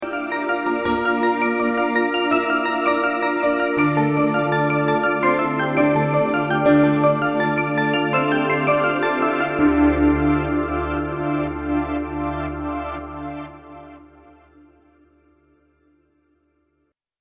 Атмоплак из Jupiter-80